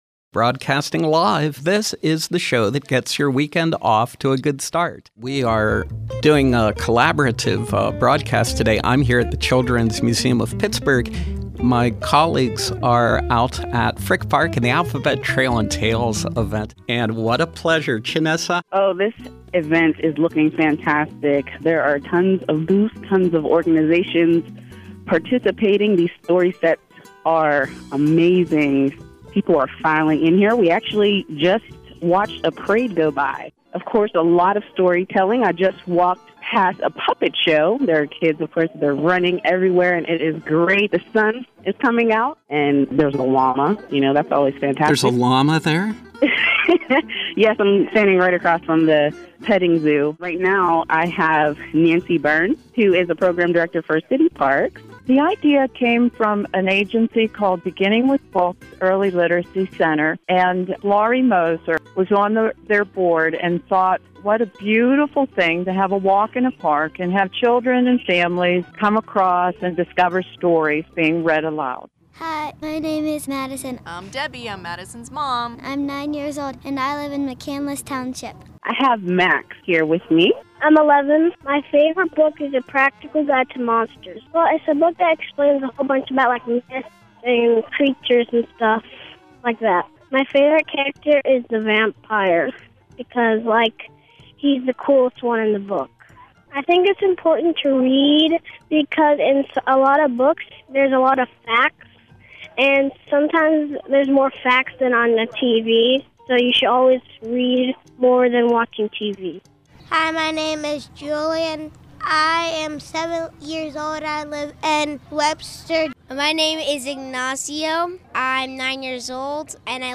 SLB was proud to be part of the 14th annual Citiparks Alphabet Trail and Tales, September 14,  2013 at the Frick Park Blue Slide Playground. In addition to providing story boxes and audio devices for children to explore, SLB recorded thoughts from kids and adults on reading and the the importance of literacy during our remote broadcast.